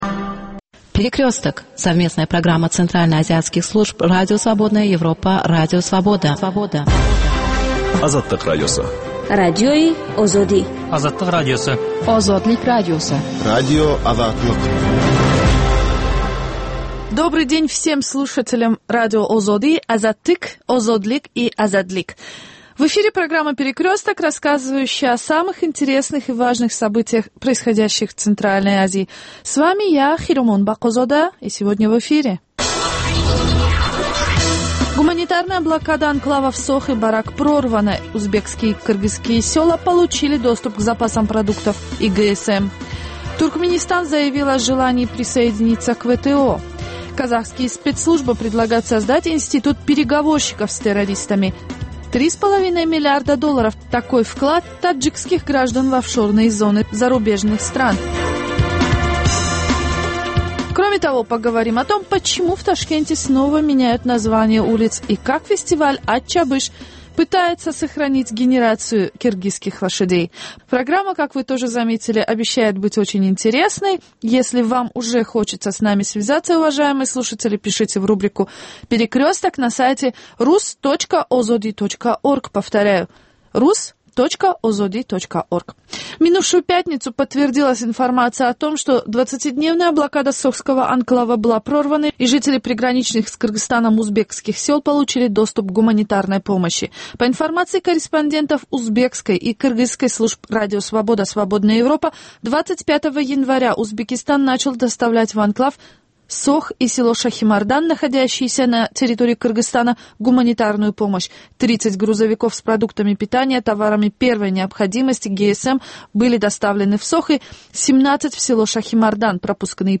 Новости стран Центральной Азии. Специальная программа на русском языке.